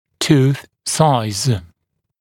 [tuːθ saɪz][ту:с сайз]размер зуба